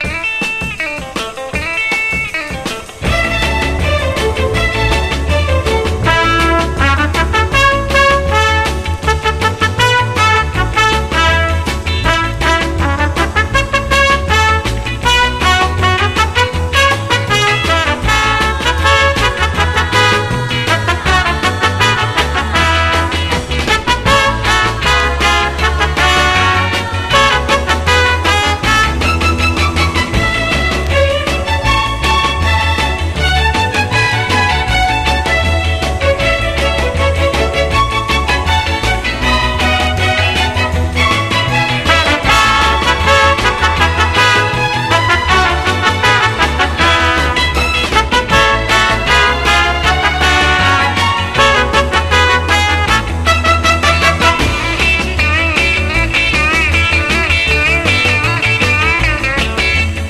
EASY LISTENING / EASY LISTENING / MOOG
エレガントなハープが肝にワカチョコ・ギターまで飛び出す